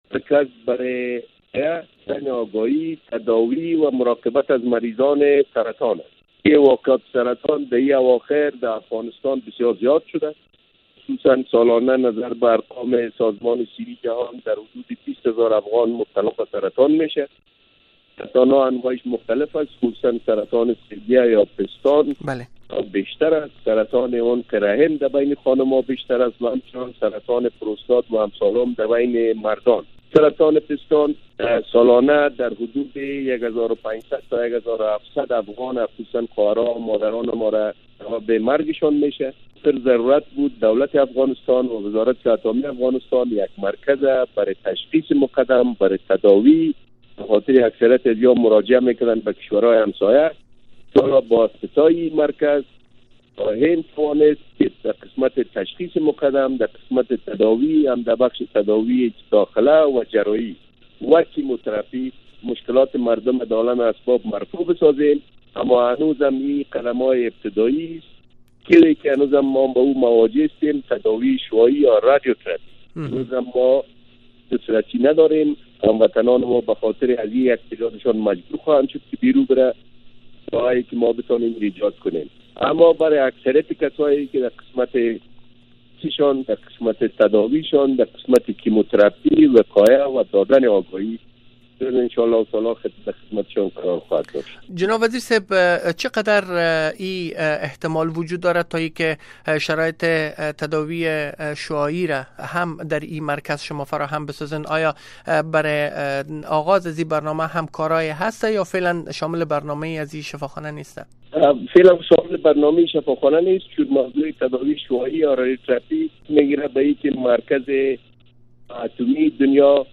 با وزیر صحت افغانستان را در این باره ازینجا بشنوید